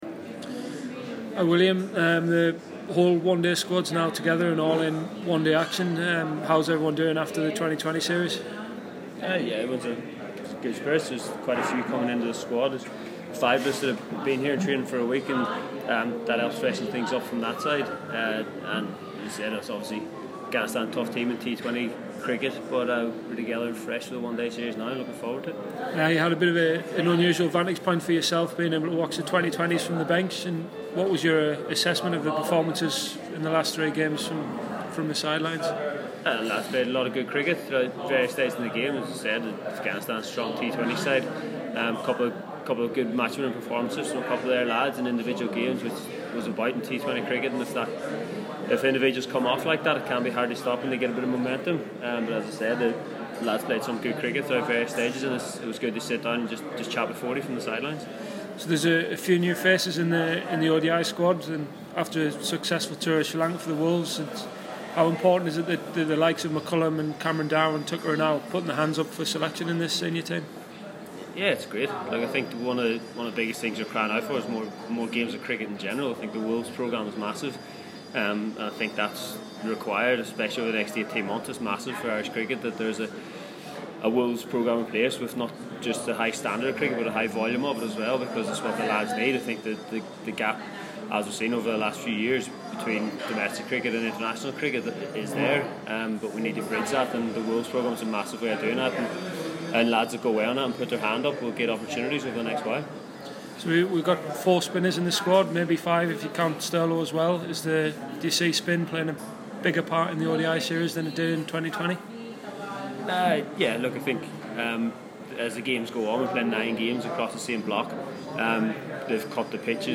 But rain it did today, and while the Ireland Men’s cricket team sat out training due to the downfall, Ireland’s long-term captain William Porterfield spoke about the upcoming one-day international series.